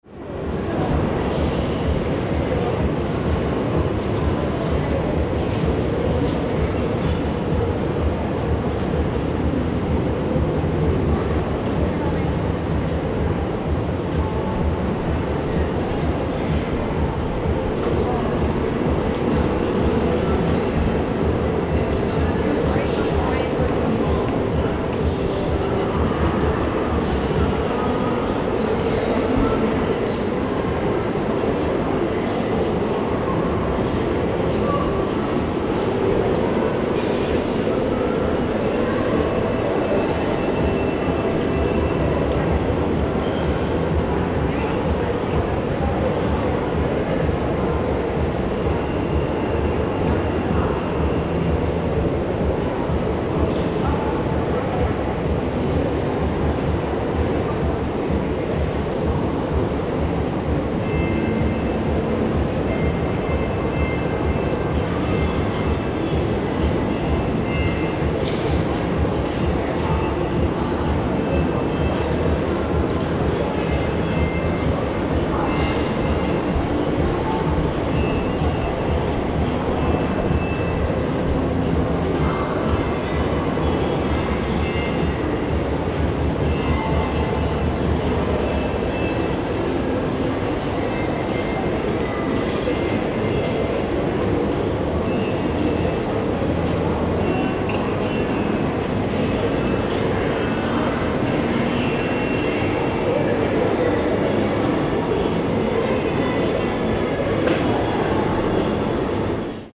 Gemafreie Sounds: Bahnfahrer
mf_SE-5589-big_hall_very_crowded_2.mp3